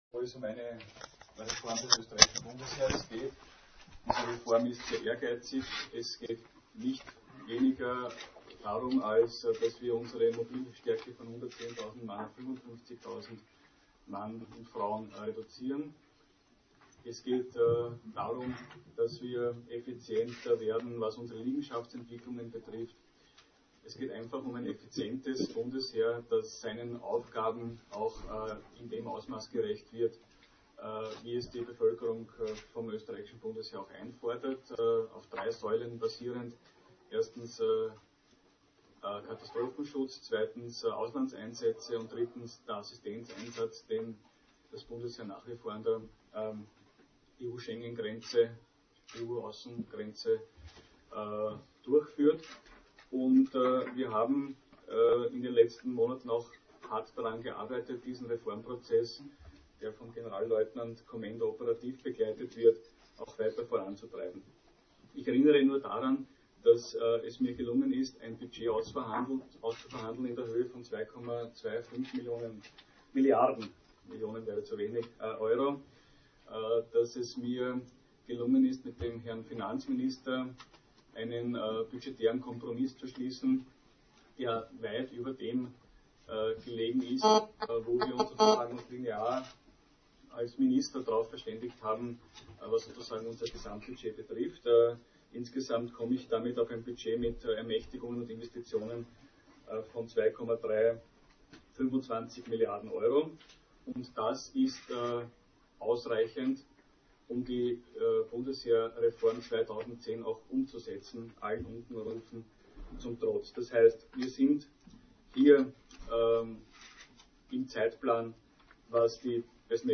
Als bei der BMLV-Pressekonferenz am 29. Mai die Reform der Zentralstelle zur Sprache kam, beschrieb Generalleutnant Othmar Commenda die Zentralstellenreform als weit fortgeschritten. Minister Darabos können in den nächsten Wochen Vorschläge vorgelegt werden, unter welche Rahmenbedingungen die Zentralstelle neu strukturiert werden könnte.